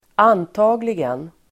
Uttal: [²'an:ta:gligen]